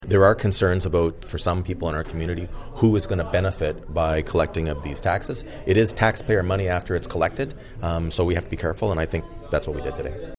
Mayor Panciuk made this comment on the discussion.